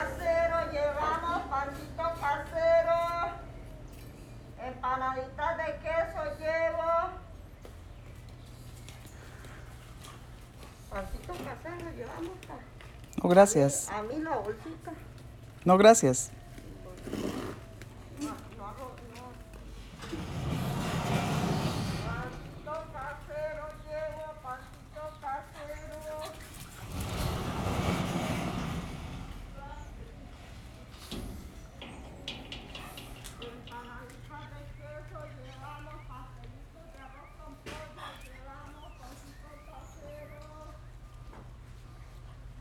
Un mapa sonoro es una técnica acústica para conocer los sonidos de un lugar, comunidad o ciudad; ubica los sonidos geográficamente.
Por ello les invitamos a que nos envíen los sonidos más representativos de su comunidad.